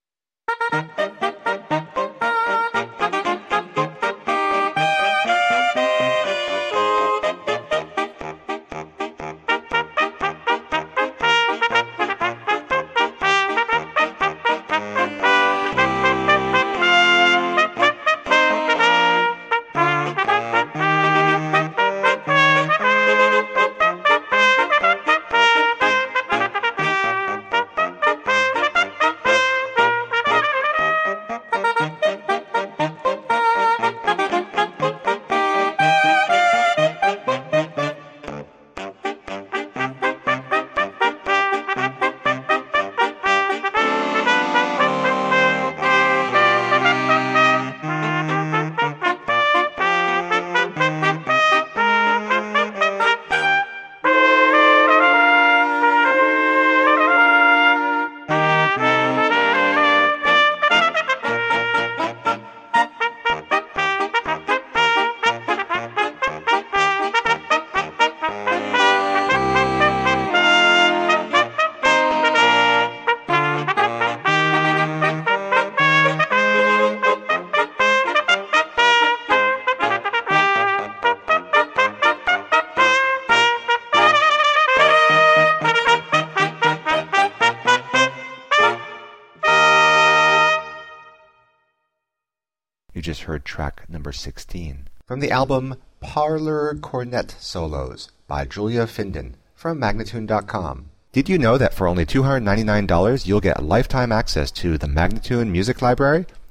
Delightful nostalgic melodies for cornet solo.